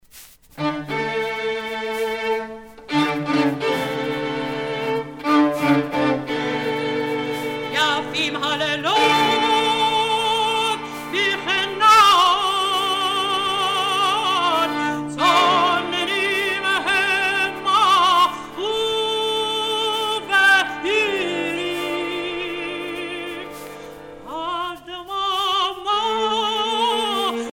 Chansons douces et chansons d'amour